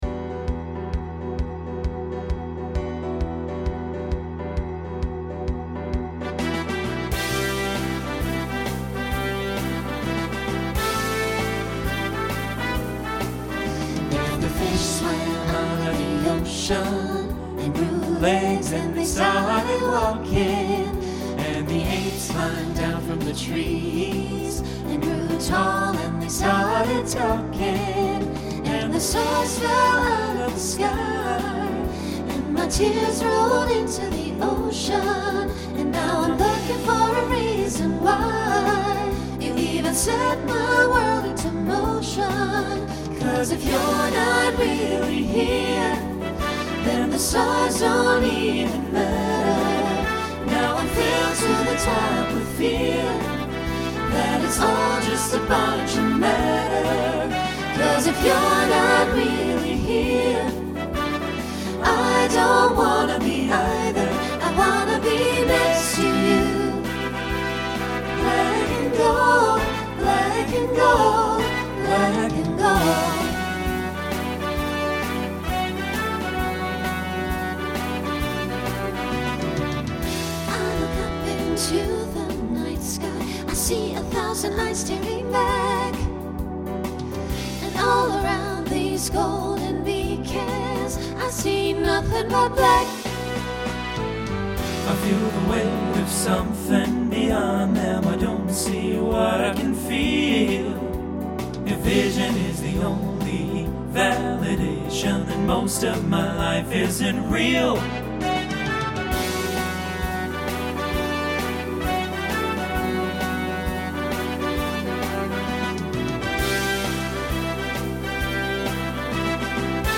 Voicing SATB Instrumental combo Genre Swing/Jazz
Mid-tempo